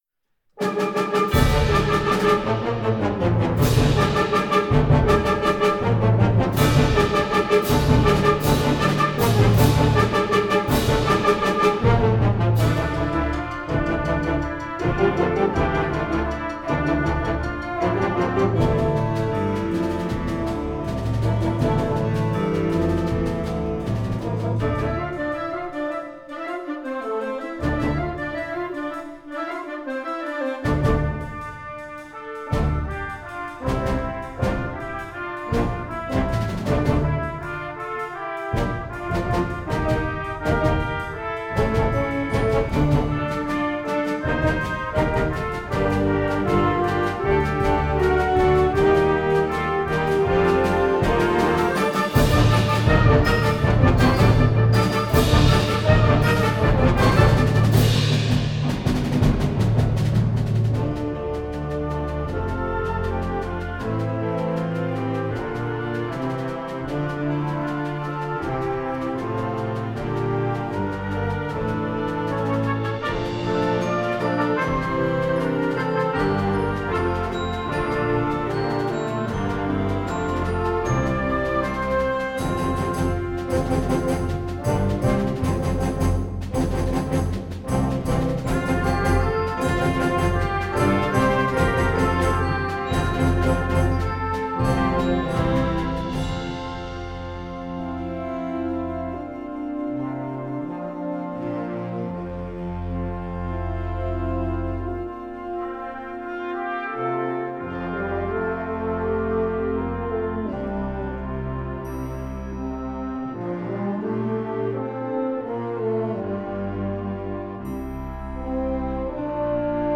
Gattung: Ouvertüre für Jugendblasorchester
Besetzung: Blasorchester
aufregende Ouvertüre